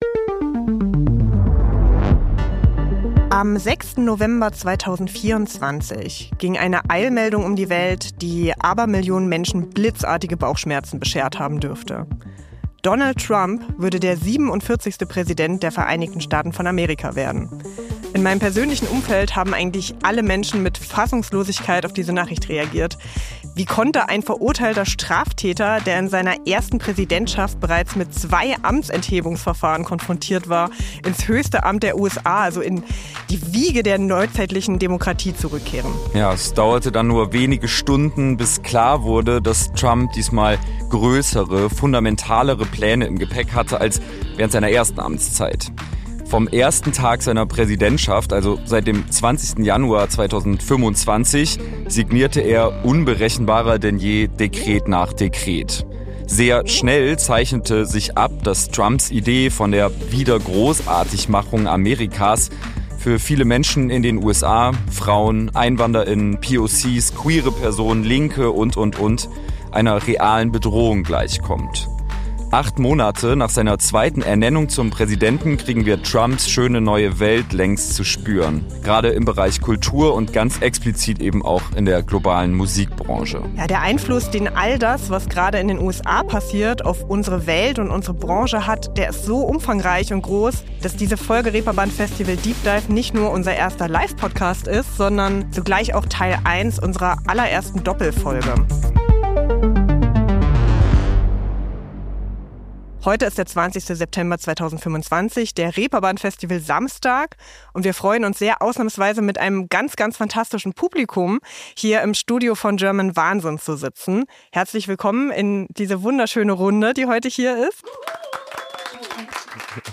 Die Episode wurde im September 2025 auf dem Reeperbahn Festival aufgezeichnet.